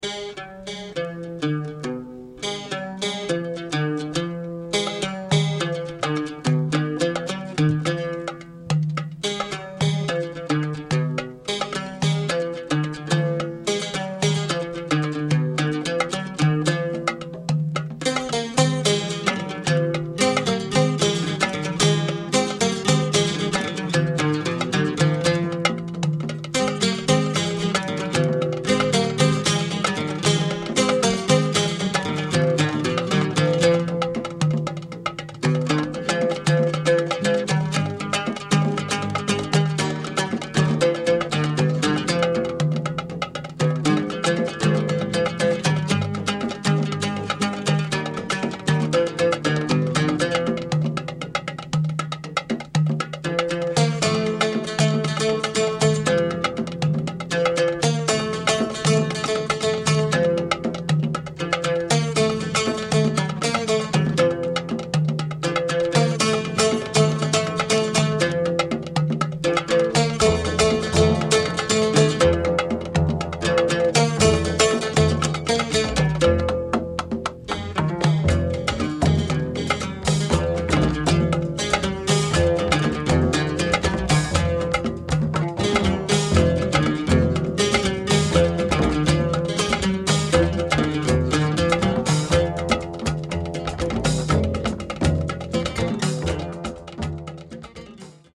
Groovy progressive folk debut